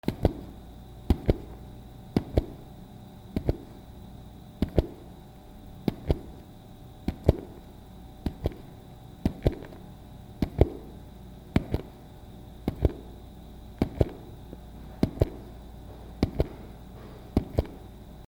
足音単音
/ I｜フォーリー(足音) / I-240 ｜足音 特殊1
残響 『ゴトゴト』